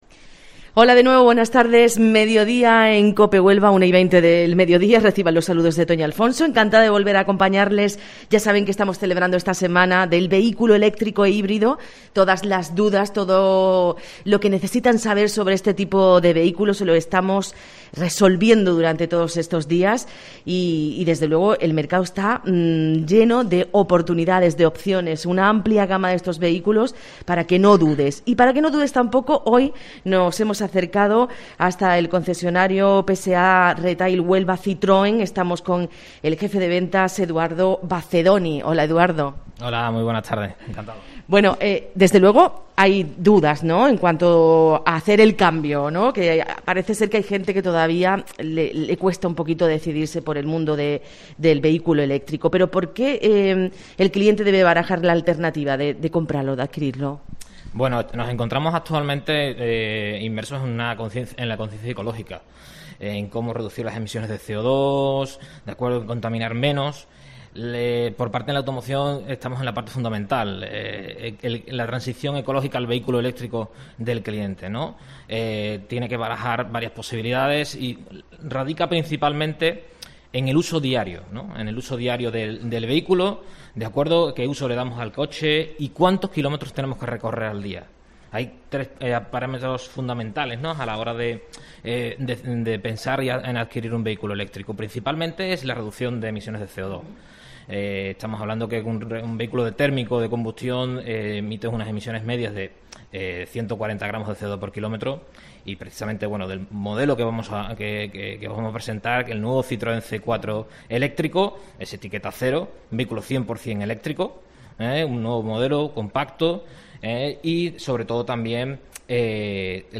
El nuevo Citroën ë-C4 eléctrico es el protagonista del Mediodía COPE Huelva que hoy realizamos desde PSA Retail en el que conocemos la gama de vehículos electrificados de la casa.